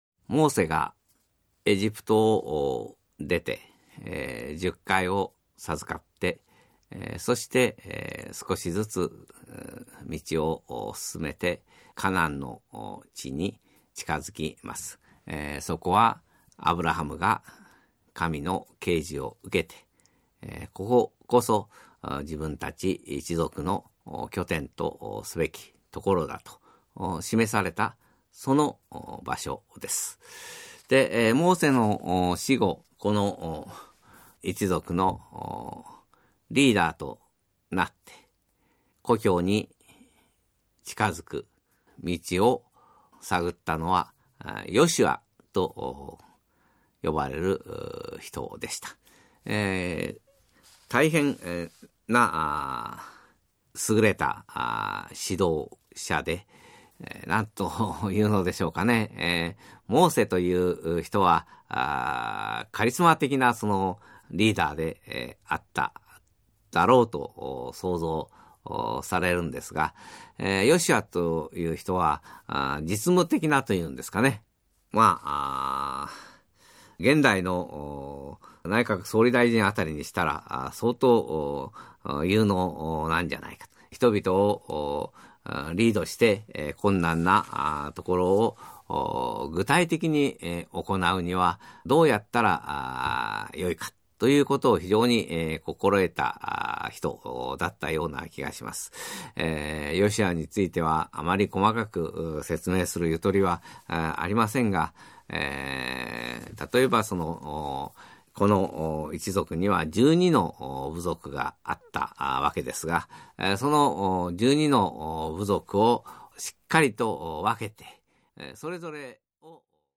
[オーディオブック] 聴く歴史・海外『旧約聖書を知っていますか？【後半】』
興味深い逸話が凝縮した、語りで聴く旧約聖書のダイジェストです。
作家 阿刀田高